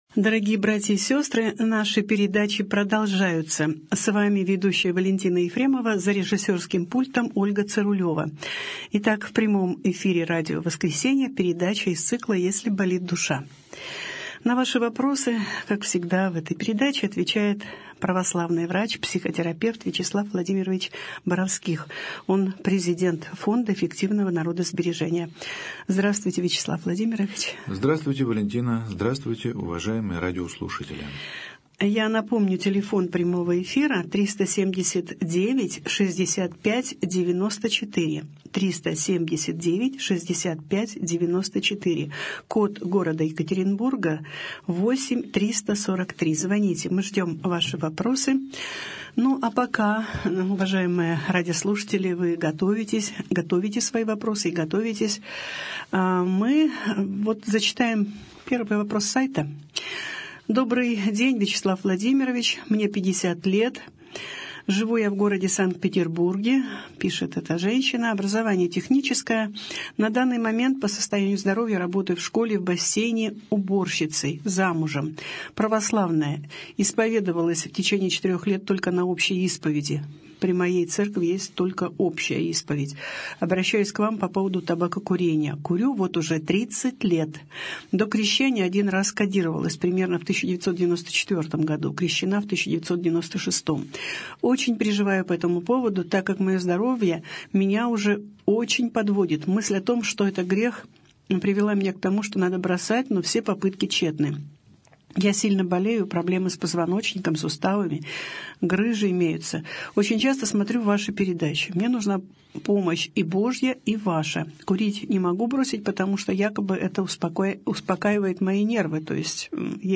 Беседа с психологом | Православное радио «Воскресение»